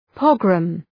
{‘pɒgrəm}